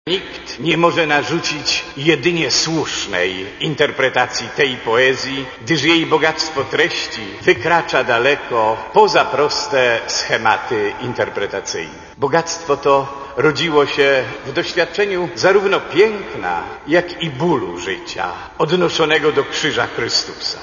Abp Józef Życiński mówił w homilii, że Miłosz, niczym współczesny św. Augustyn, szukał i wątpił, kochał i cierpiał. Rozdarty między bólem życia a pięknem świata, poszukiwał w rozważaniu Słowa Bożego źródeł nadziei i przeciwdziałania zniechęceniom.